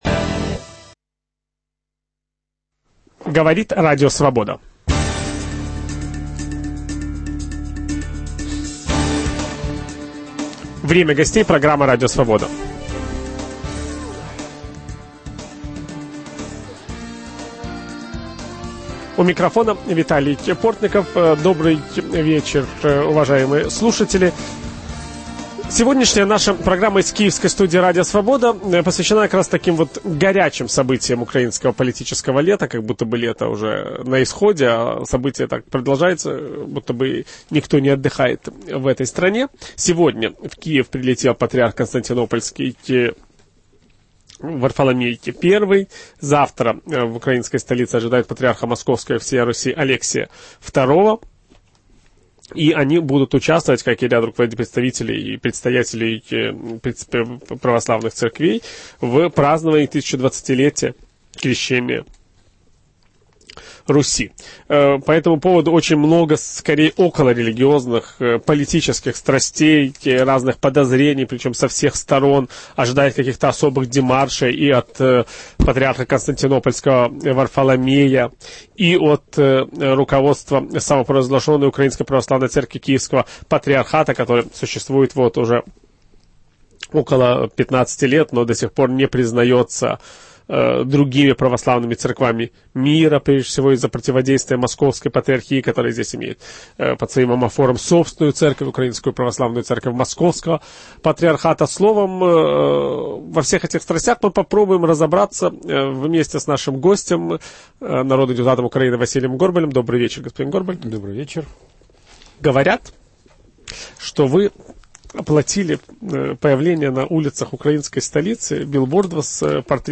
Что происходит вокруг празднований 1020-летия крещения Руси? Ведущий программы Виталий Портников беседует с народным депутатом Украины Василием Горбалем.